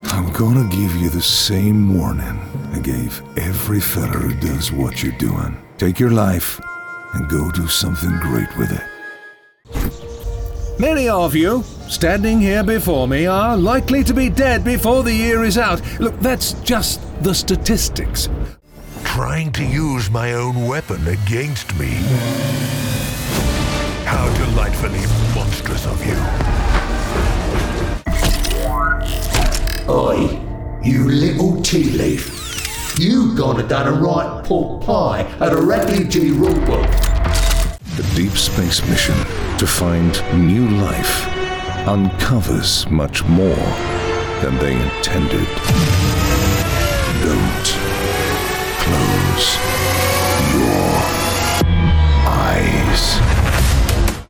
Anglais (britannique)
Jeux vidéo
Aston Spirit microphone
Fully acoustic/sound treated recording environment